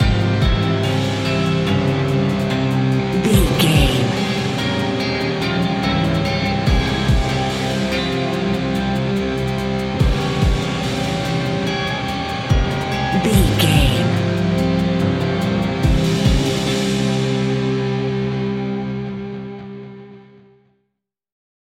Fast paced
In-crescendo
Ionian/Major
industrial
dark ambient
EBM
drone
synths
Krautrock